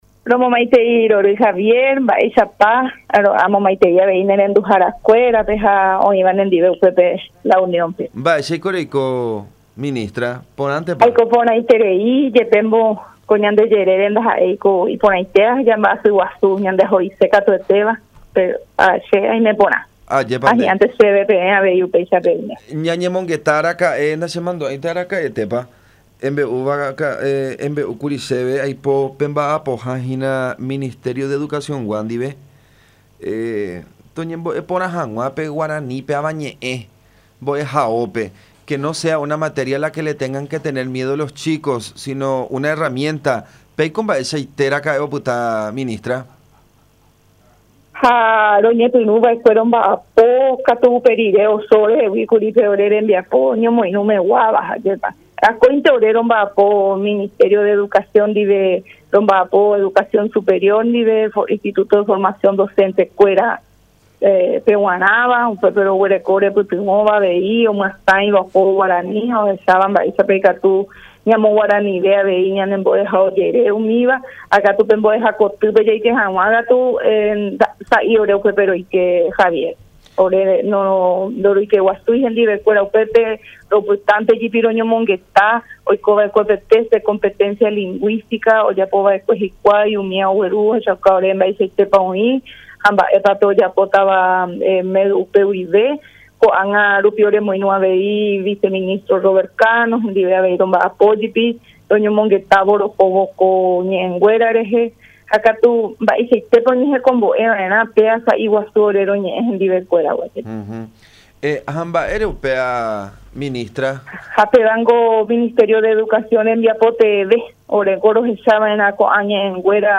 Ladislaa Alcaraz de Silvero, ministra de la Secretaría de Políticas Lingüísticas expresó que el Guaraní es una lengua viva que se encuentra fuerte y que goza de buena salud.